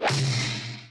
Hit Cartoon Sound Effect - Bouton d'effet sonore